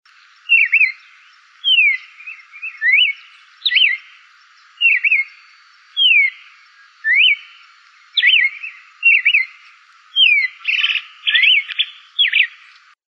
Cardenal Común (Paroaria coronata)
Nombre en inglés: Red-crested Cardinal
Fase de la vida: Adulto
Localidad o área protegida: Reserva Ecológica Costanera Sur (RECS)
Condición: Silvestre
Certeza: Vocalización Grabada